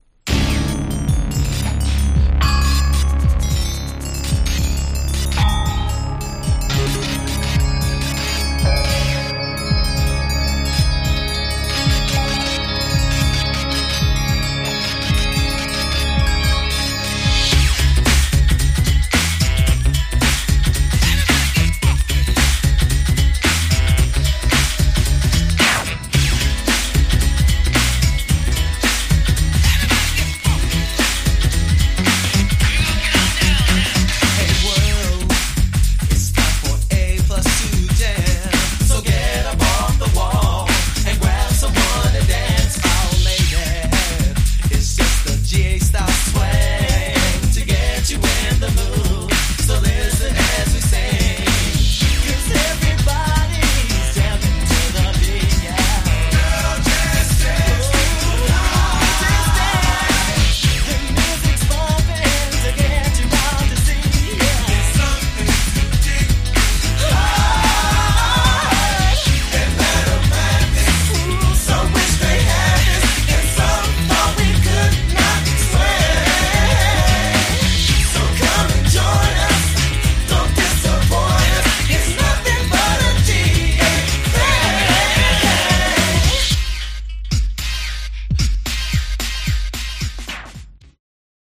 マイナー良質New Jack Swing !!
ハネてます！！